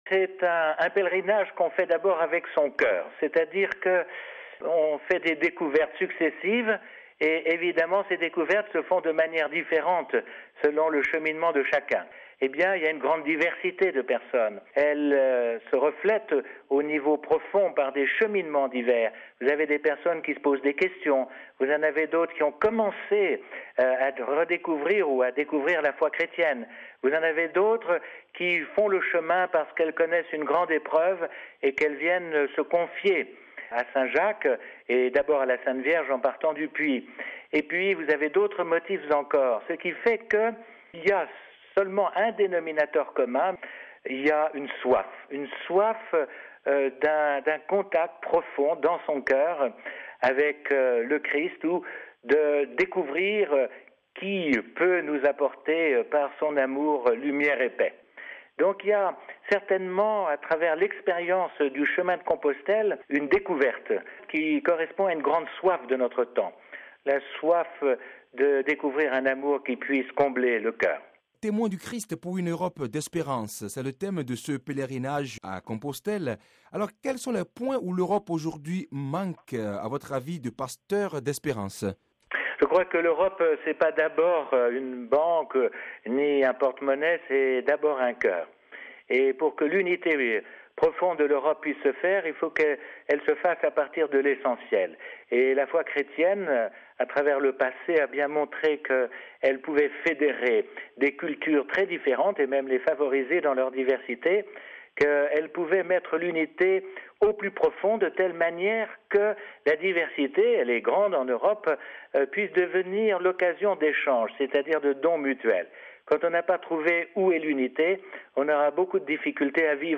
Mgr Henri Brincard est l’Evêque de Puy en Velai, une ville d’où partent chaque jour des centaines de pèlerins.